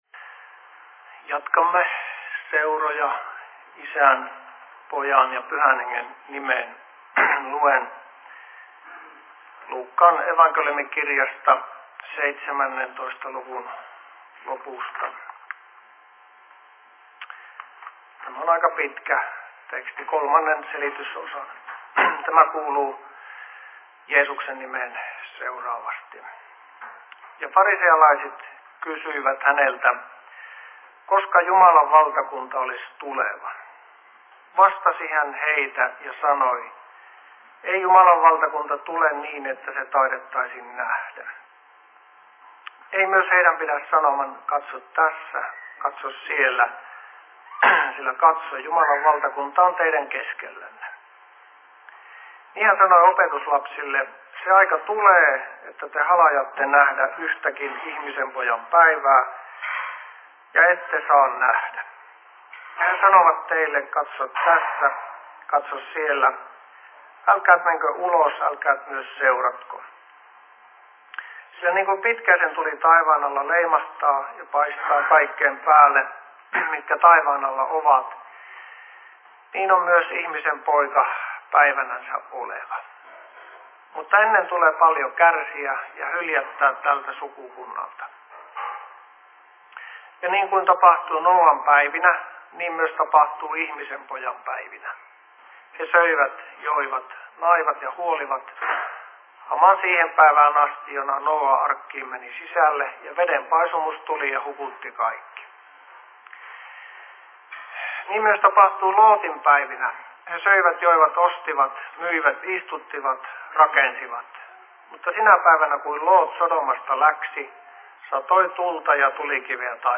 Seurapuhe 02.10.2011
Paikka: Rauhanyhdistys Ruukki